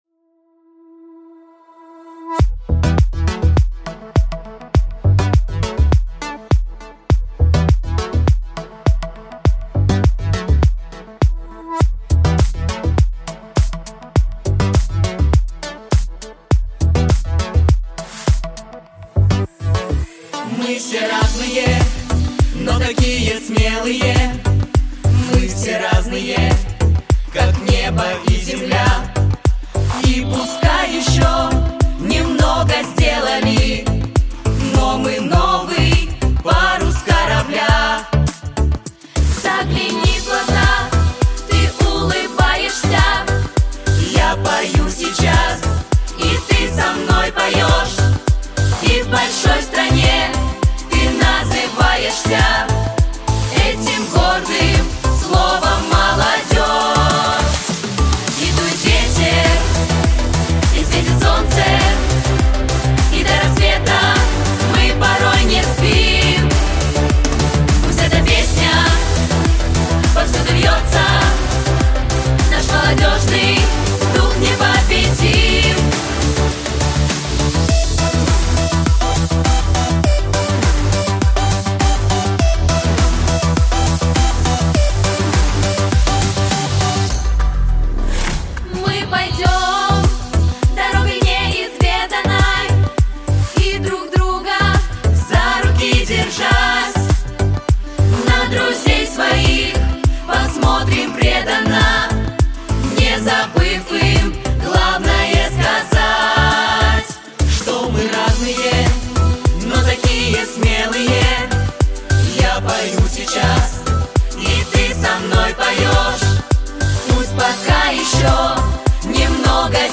• Категория: Детские песни
Вокальный ансамбль